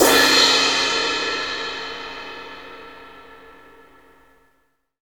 Index of /90_sSampleCDs/Roland - Rhythm Section/CYM_FX Cymbals 1/CYM_Cymbal FX
CYM BRUSH 01.wav